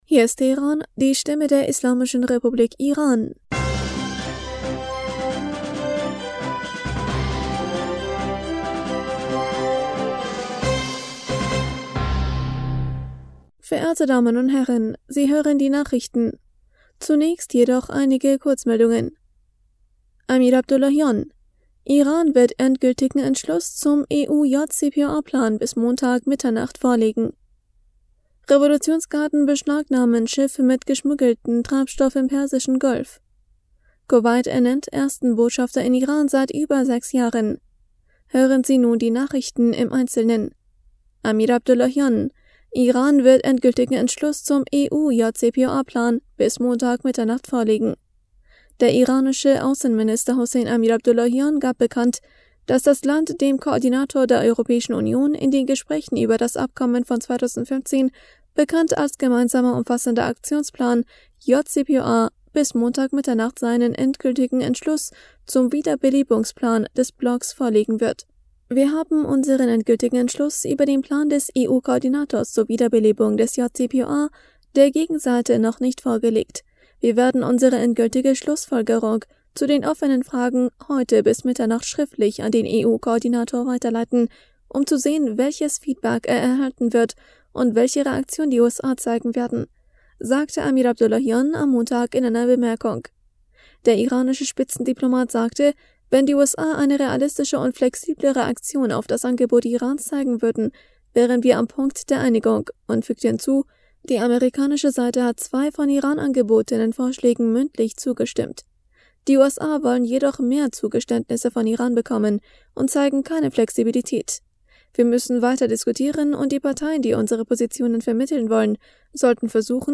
Nachrichten vom 15. August 2022
Die Nachrichten von Montag, dem 15. August 2022